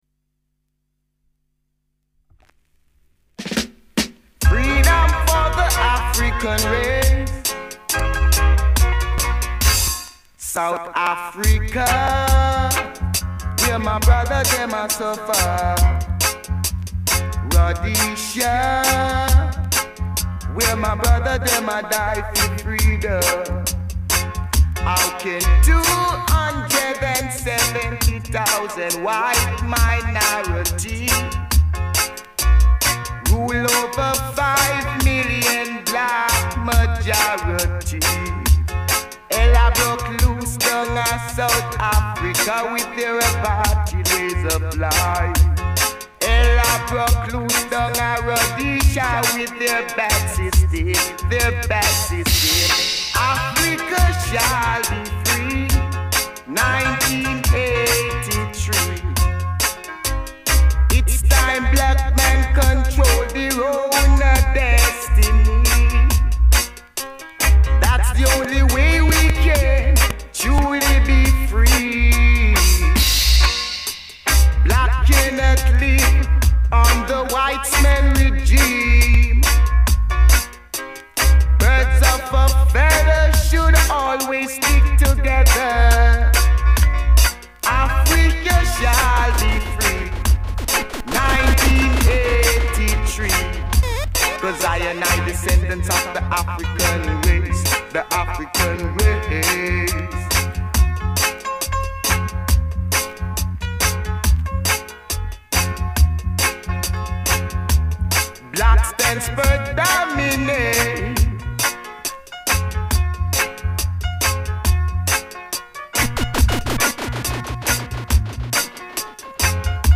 Podcast of the week, old and modern roots music.